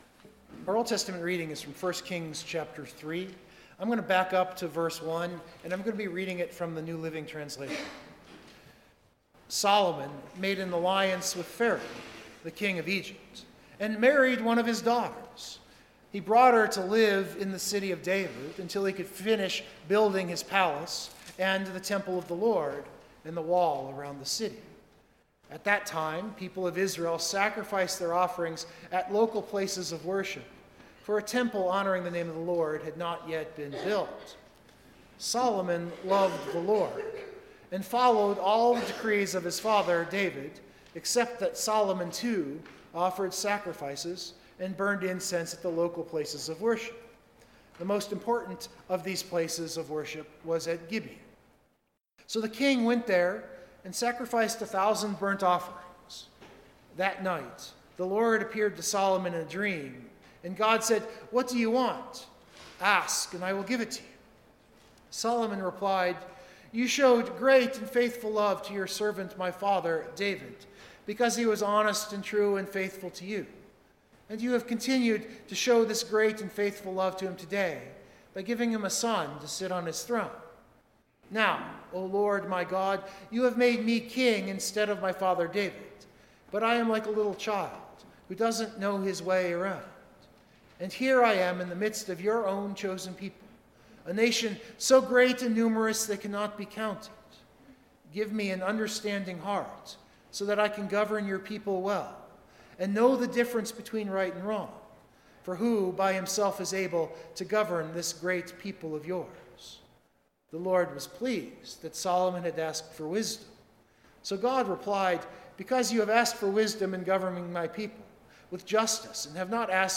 The sermon I’ve labeled a New Year meditation because at New Year we often think or plan or just dream about what kind of growth we might attempt over the next year.